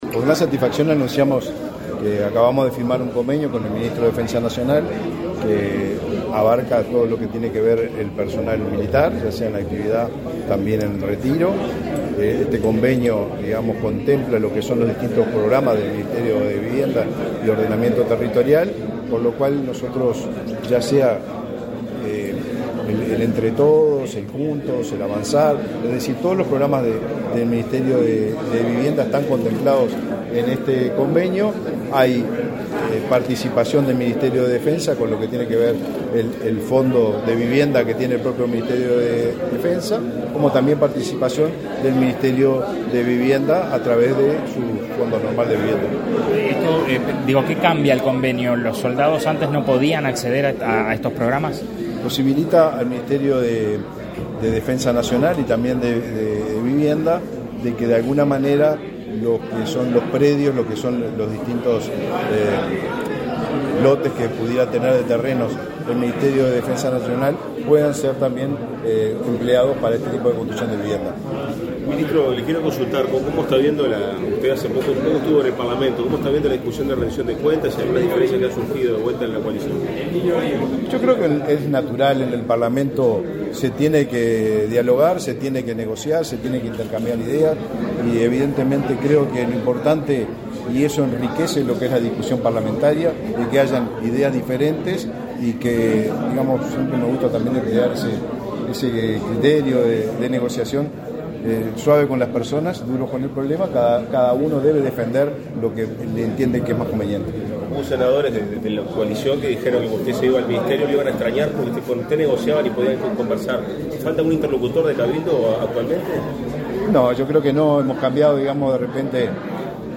Declaraciones a la prensa del ministro de Vivienda y Ordenamiento Territorial, Raúl Lozano
Los ministros de Defensa Nacional, Javier García, y de Vivienda y Ordenamiento Territorial, Raúl Lozano, firmaron un convenio, este 18 de agosto, para que los funcionarios de Defensa y los retirados, jubilados y pensionistas del Servicio de Retiros y Pensiones de las Fuerzas Armadas accedan a soluciones habitacionales. Tras el evento, el ministro Lozano realizó declaraciones a la prensa.